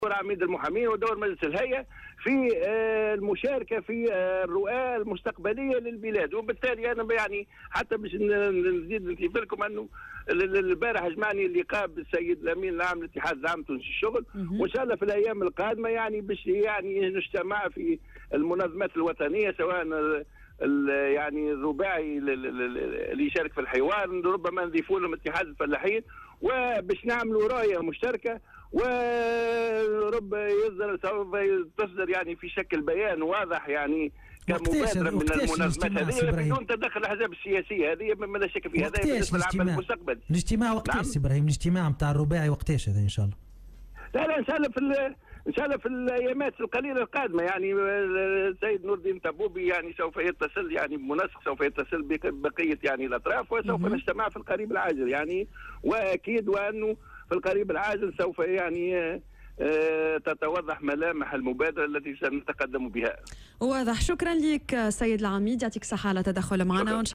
وأضاف في مداخلة له اليوم على "الجوهرة أف أم" أنه التقى يوم أمس الأمين العام لاتحاد الشغل، نور الدين الطبوبي وتم الاتفاق على عقد اجتماع بين المنظمات الوطنية خلال الأيام القليلة القادمة لصياغة رؤية مشتركة من إجل إيجاد حلول للأزمة الراهنة، وفق قوله.